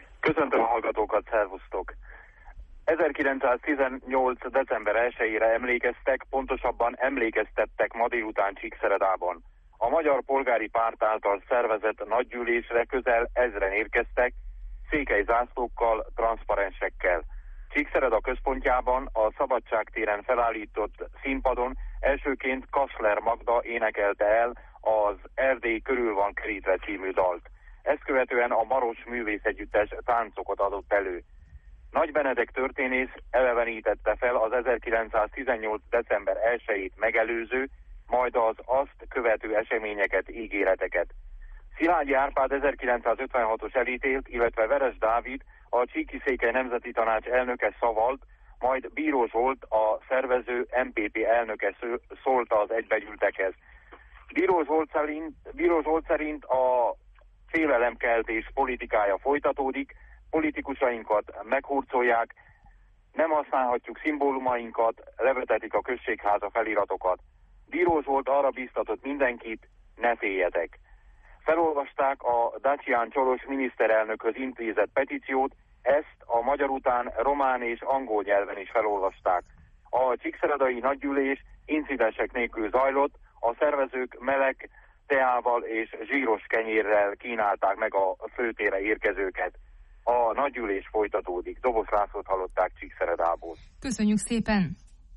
tudósít az eseményről.